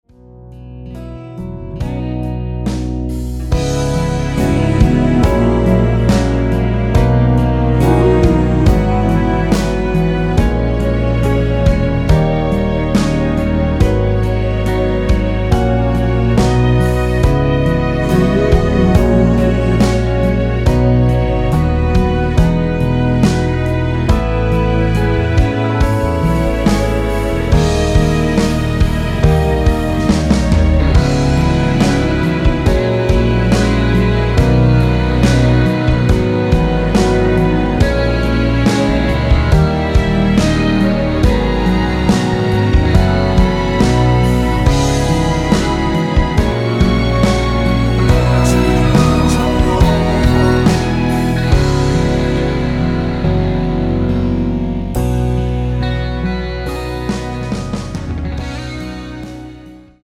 원키에서(-3)내린 코러스 포함된 MR 입니다.(미리듣기 참조)
앞부분30초, 뒷부분30초씩 편집해서 올려 드리고 있습니다.